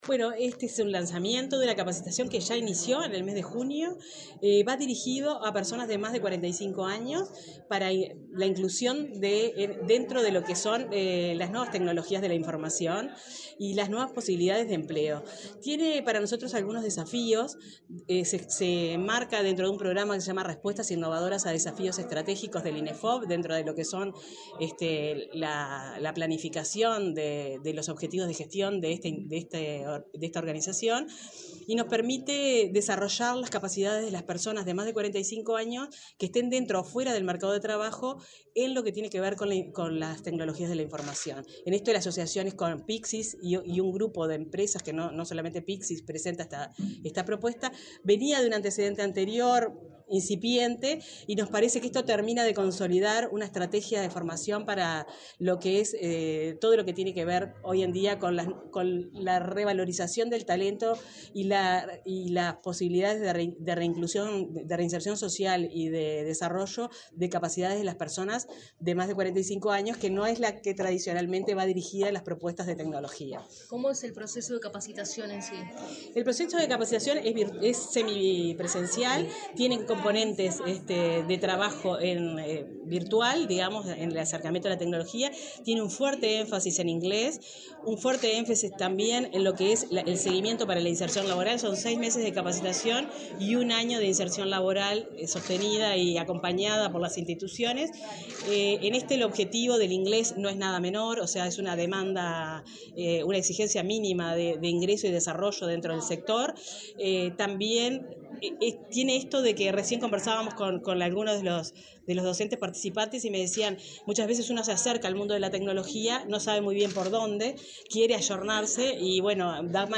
Declaraciones de la directora del Inefop por el MEC, Marisa Acosta
La directora del Instituto Nacional de Empleo y Formación Profesional (Inefop) por el Ministerio de Educación y Cultura (MEC), Marisa Acosta, dialogó con la prensa, antes de participar en la presentación del programa de capacitación destinado a la reinserción de personas mayores de 45 en el sector de las tecnologías de la información.